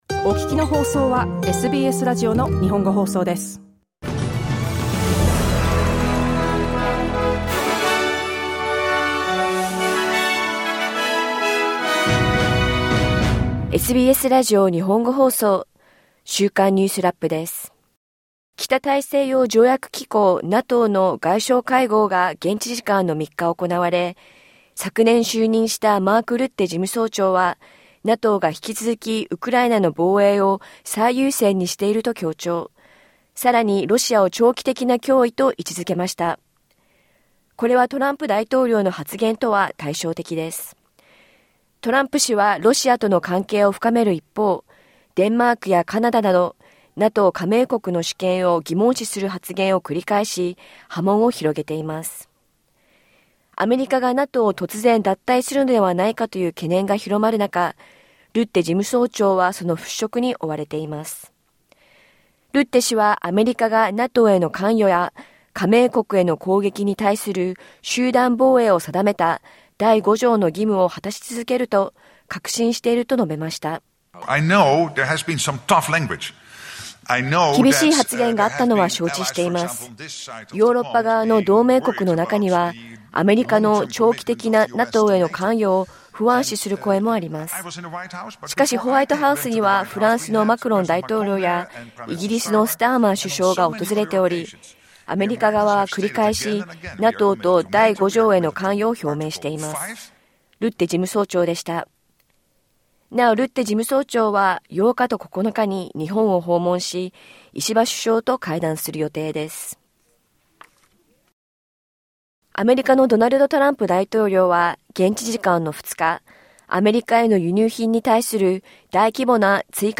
次期連邦選挙では、有権者のおよそ半数が、ミレニアル世代とZ世代が占める見通しで、初めてベビーブーマー世代を上回ることがわかりました。1週間を振り返るニュースラップです。